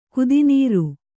♪ kudi nīru